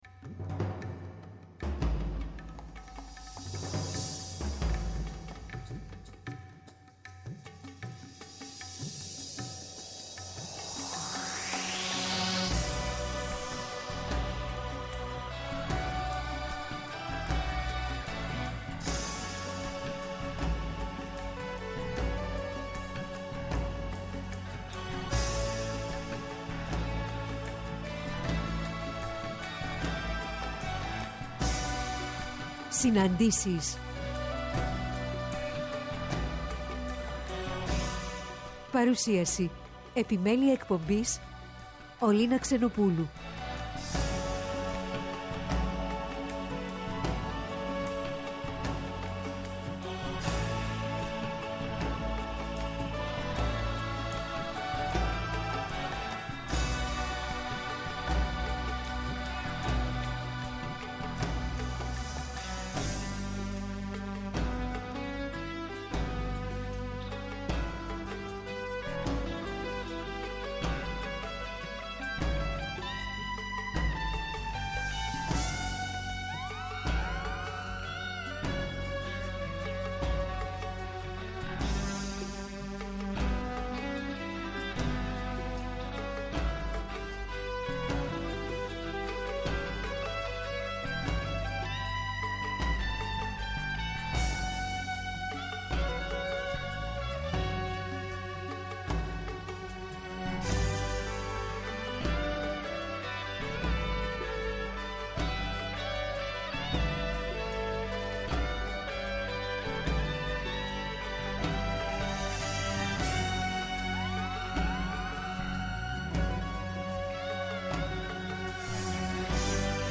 Αποκαλυπτικές Συναντήσεις με πρόσωπα της κοινωνικής, πολιτικής και πολιτιστικής τρέχουσας επικαιρότητας με τη μουσική να συνοδεύει, εκφράζοντας το «ανείπωτο».